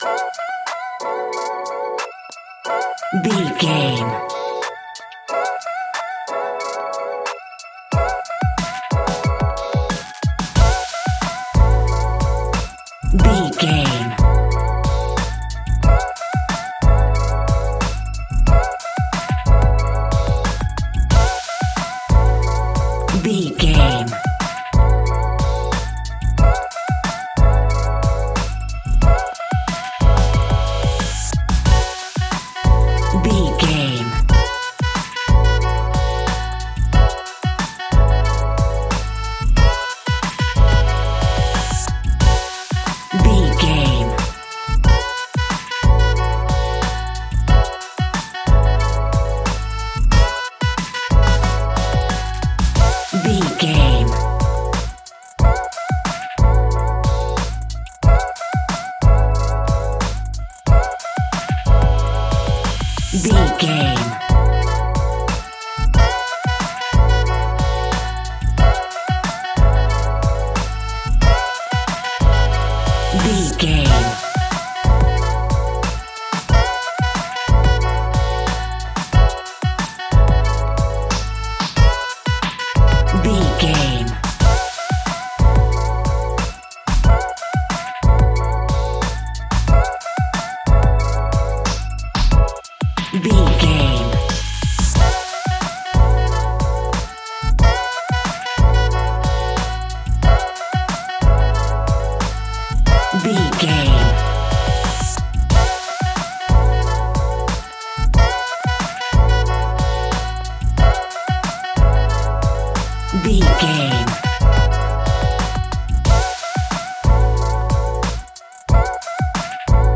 Aeolian/Minor
F♯
dreamy
relaxed
smooth
groovy
synthesiser
drum machine
bass guitar
vocals
electric piano
saxophone